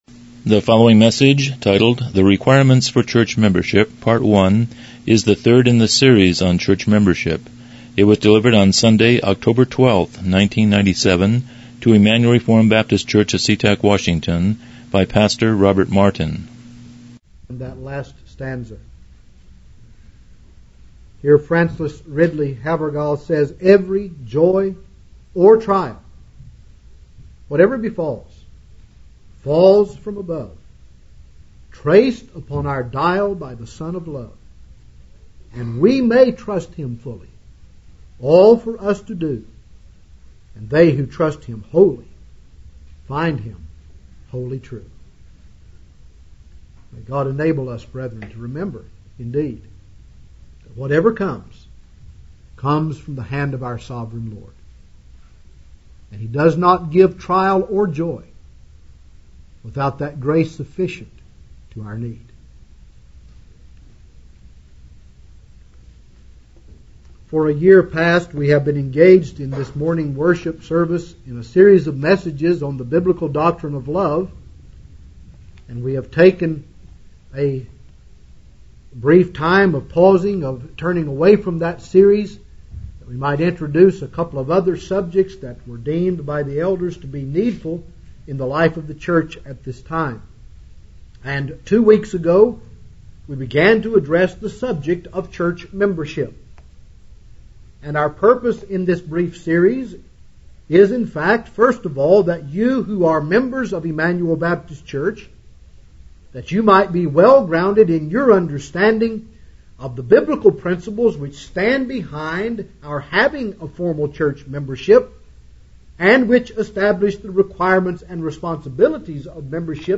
Church Membership Service Type: Morning Worship « 02 The Importance of Church Membership 04 Requirements for